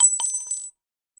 sfx_cassing_drop_1.mp3